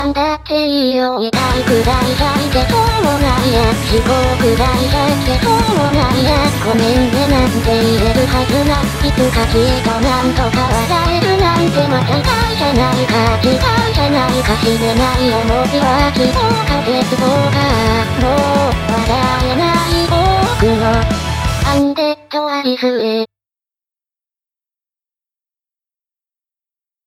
To remove it, I just did a pre-eq to remove 10KHz to 20KHz:
This adds our high freq.